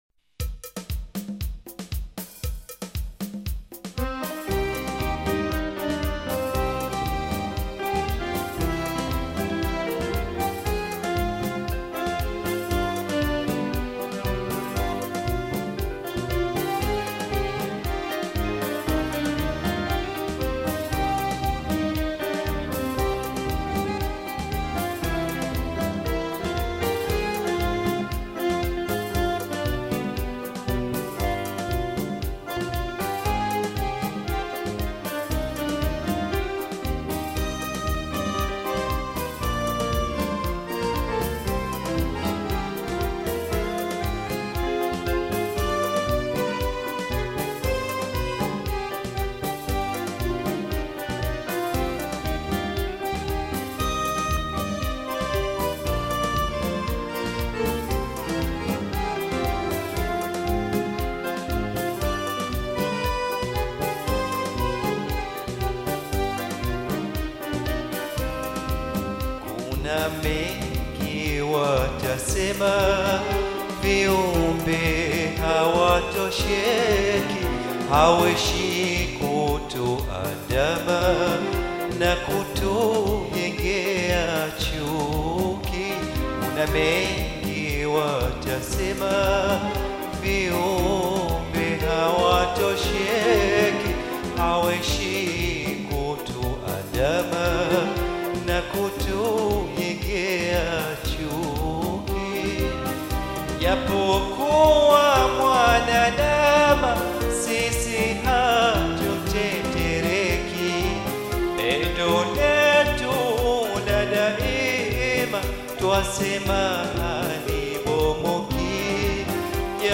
Taarab